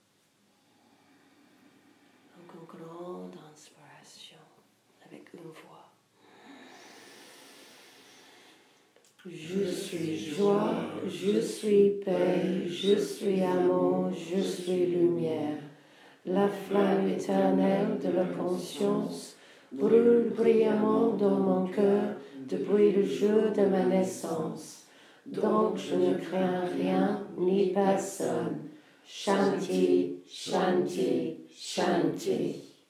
Affirmation